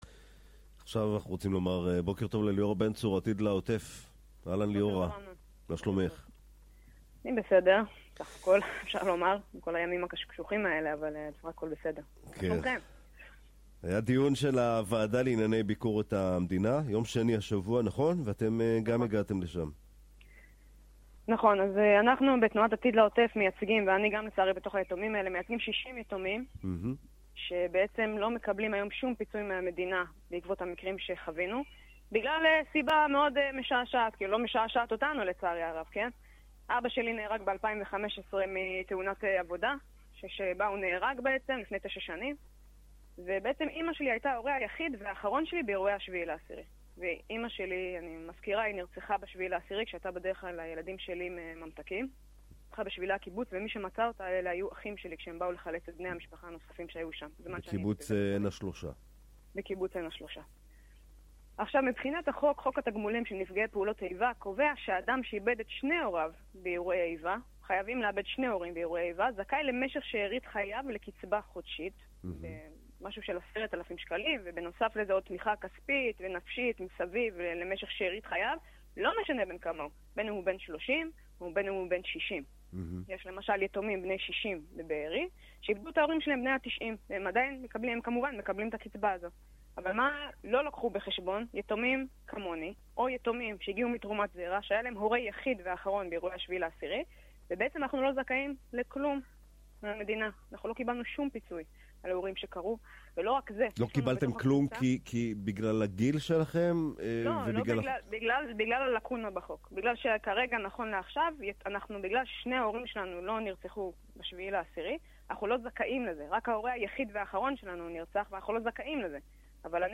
לצפיה בכתבה 👈 ראיון ברדיו דרום על מאבק היתומים שההורה היחיד והאחרון שלהם נרצח באירועי ה7.10.